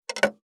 549魚切る,肉切りナイフ,
効果音厨房/台所/レストラン/kitchen食器食材
効果音